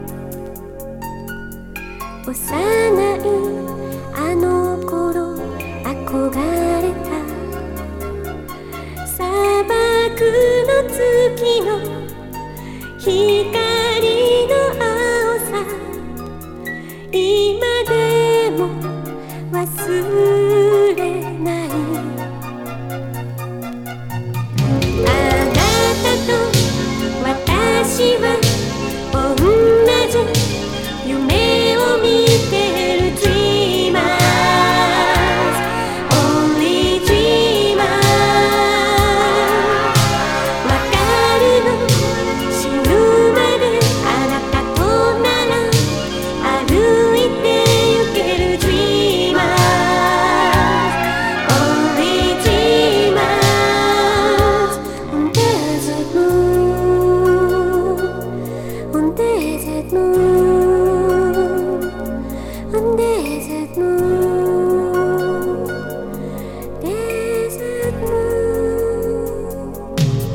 日本語カヴァー！
80's ロック / ポップス